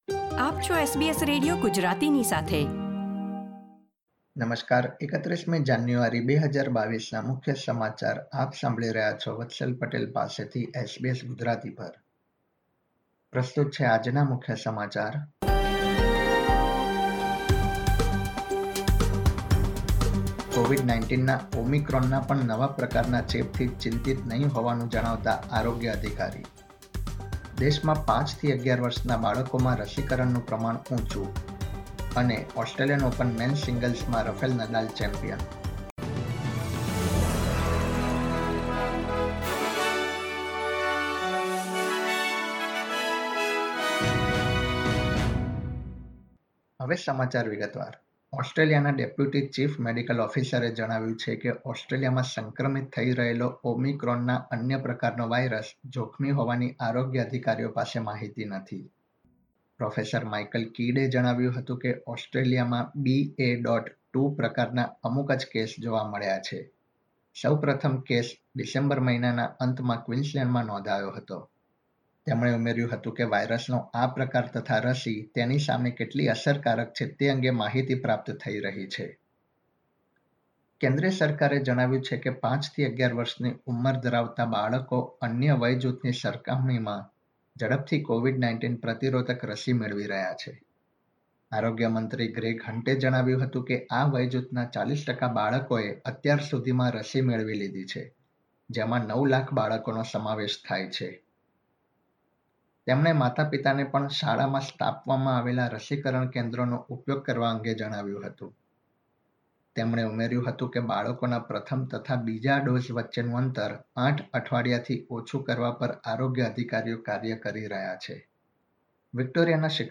SBS Gujarati News Bulletin 31 January 2022